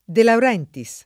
[ de laur $ nti S ]